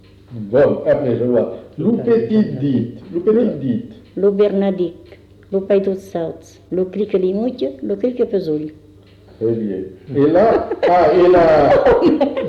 Lieu : Cazalis
Genre : forme brève
Type de voix : voix de femme
Production du son : récité
Classification : formulette enfantine